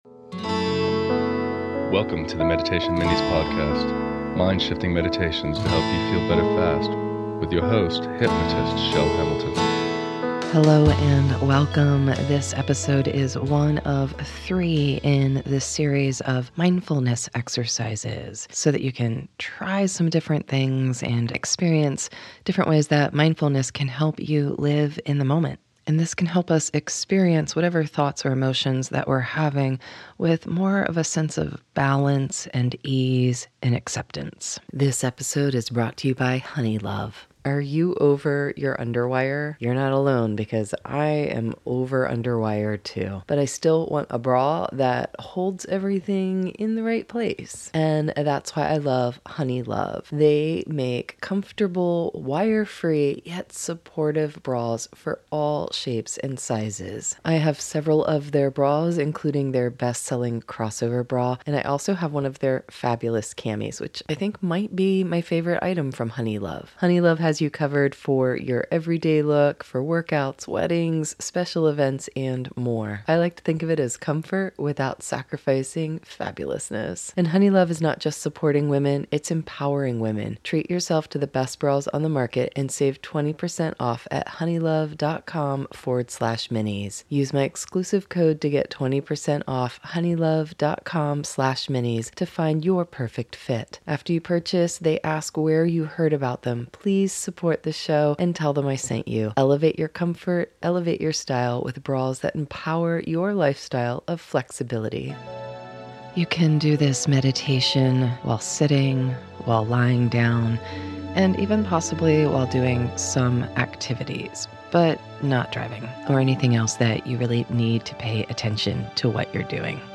This meditation is the first of three in a series of Mindfulness Meditation practices for you to try for yourself. And your can totally do this relaxing meditation laying down if you choose too.